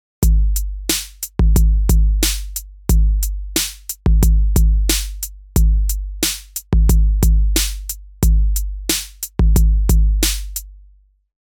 最初にダウンロードした「Beat Tape」を使ってみようと思うので、ドラムパターンのジャンルはヒップホップに決定。
シンプルなヒップホップのドラムパターン
ここでは90に変更。